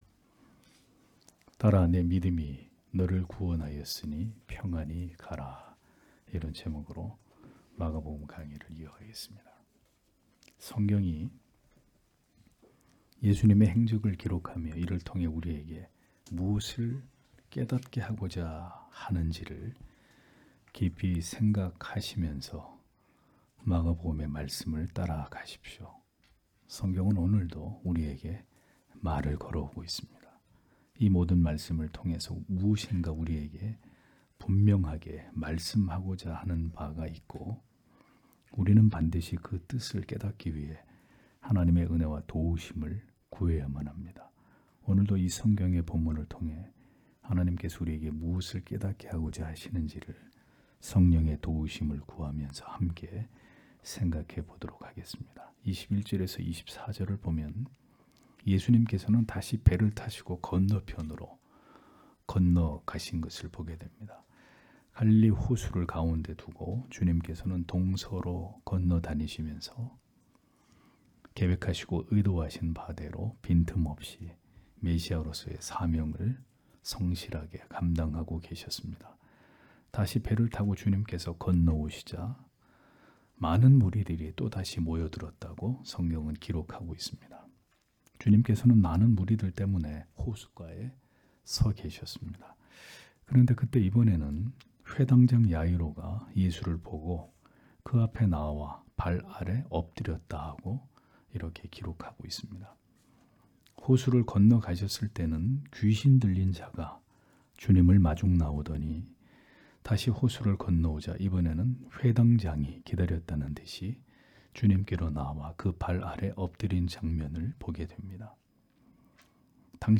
주일오전예배 - [마가복음 강해 18] 딸아 네 믿음이 너를 구원하였으니 평안히 가라 (막 5장 21-34절)